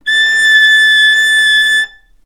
vc-A6-ff.AIF